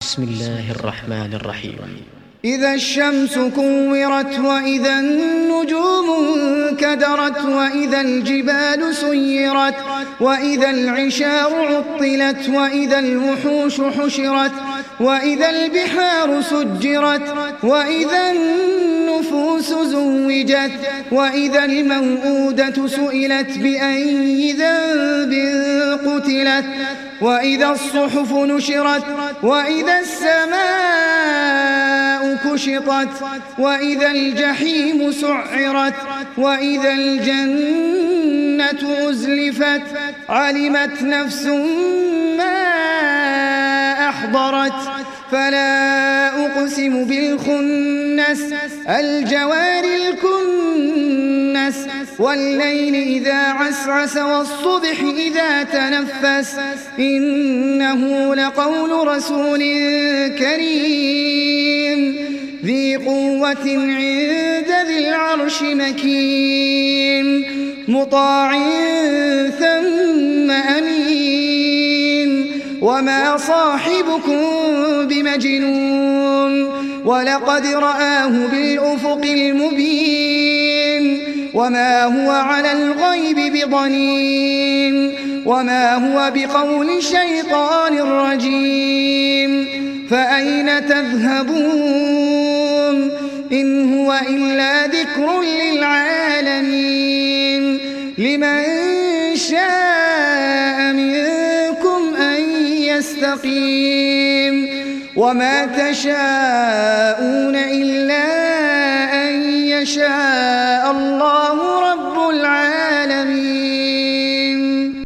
تحميل سورة التكوير mp3 بصوت أحمد العجمي برواية حفص عن عاصم, تحميل استماع القرآن الكريم على الجوال mp3 كاملا بروابط مباشرة وسريعة